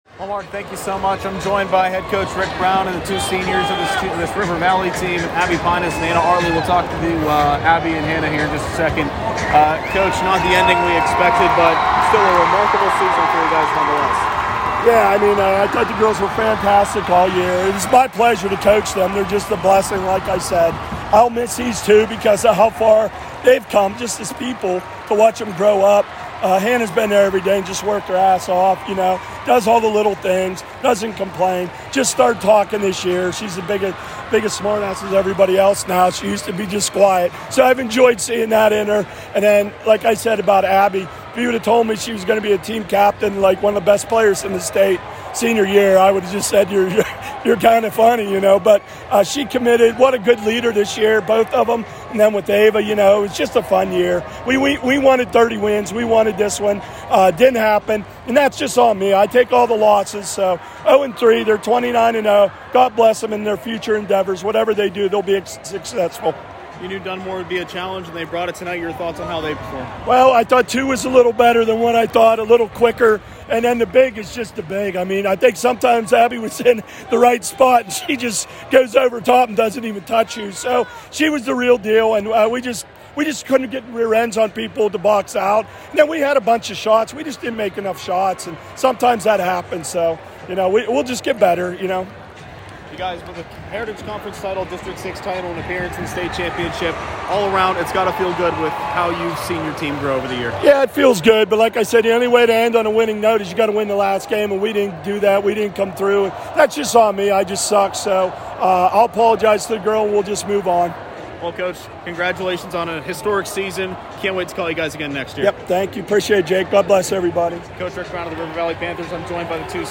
rv-postgame.mp3